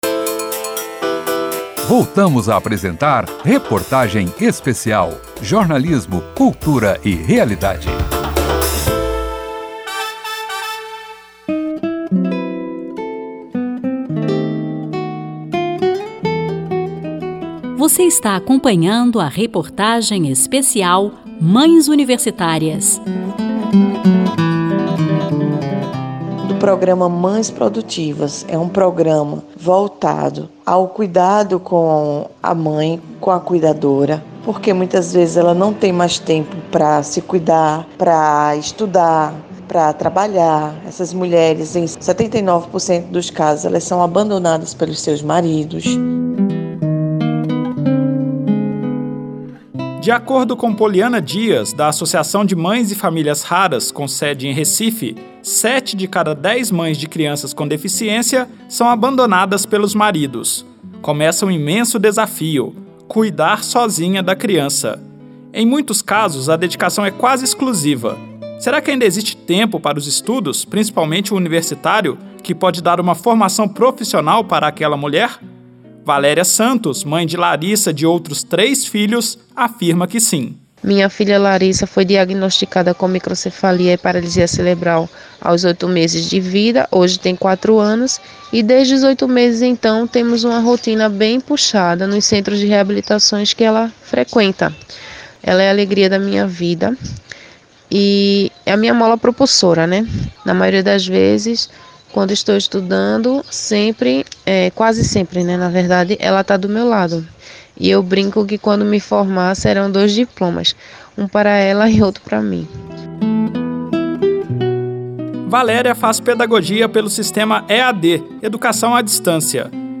Série de reportagens apresenta os desafios pessoais de estudantes na graduação e na pós-graduação